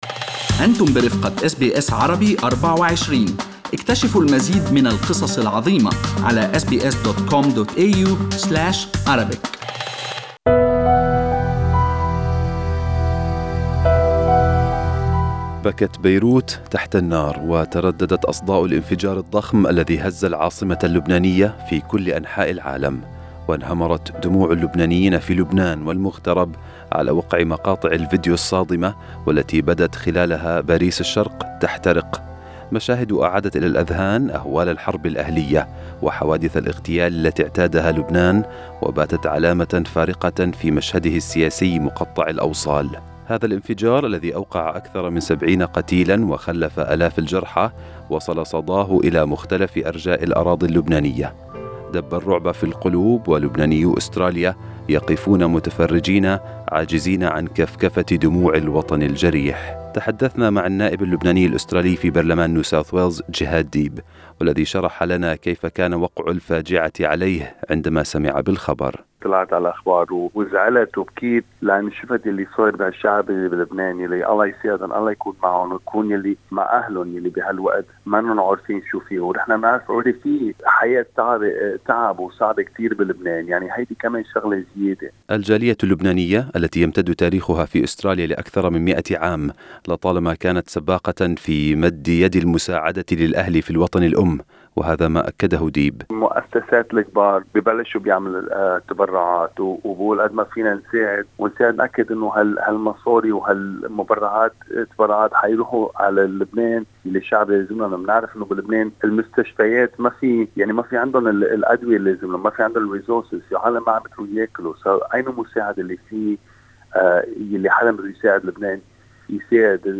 تقرير يتضمن أصوات